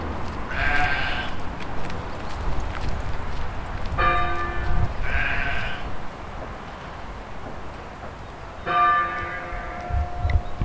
sheep.baaing and one hit church bell.wav
Sheep baaing, bleating and yelling and a church bell in the hills, recorded with a Tascam DR 40.
sheep_.baaing_and_one_hit_church_bellwav_x4d.ogg